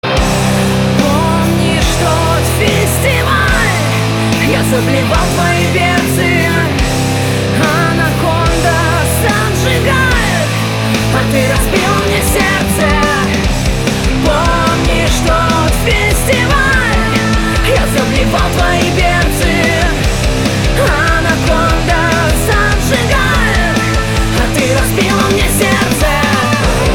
• Качество: 320, Stereo
жесткие
женский голос
Рэп-рок